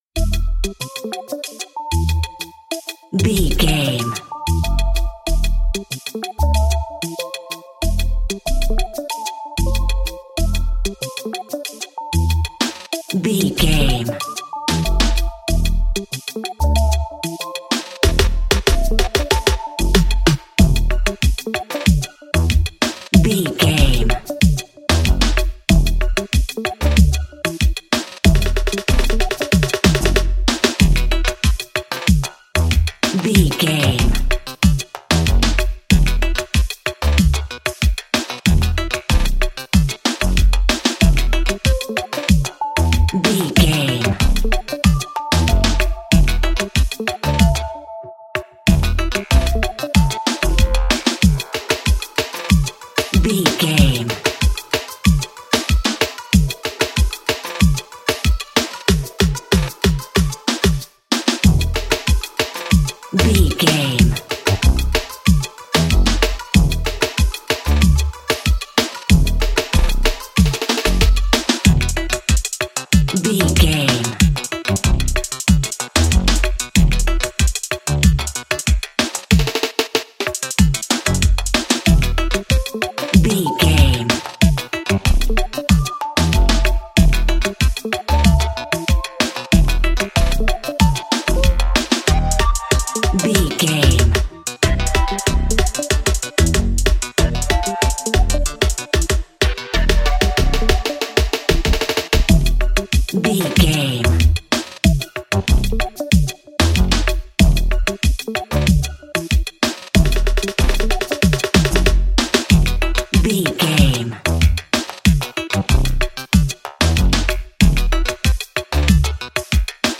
Ionian/Major
warm optimistic
energetic
festive
reggaeton